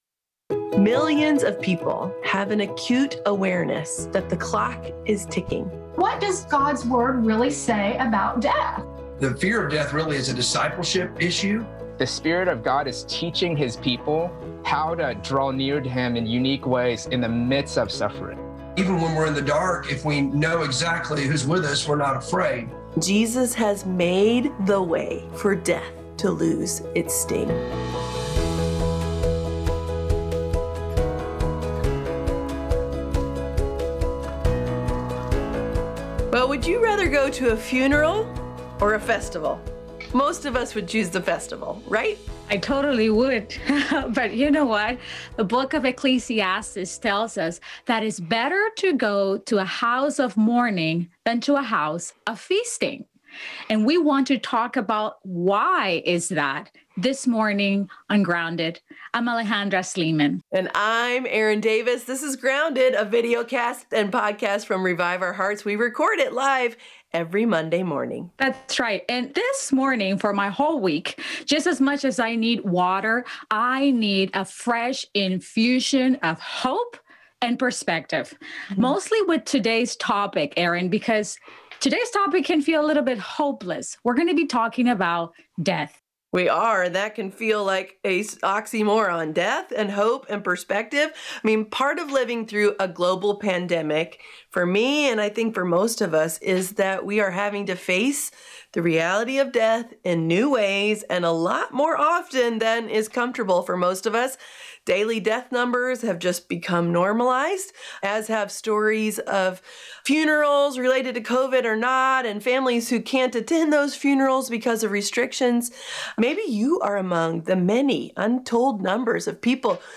In this difficult, yet important, conversation, we’ll see what the Bible says about hope-filled grief.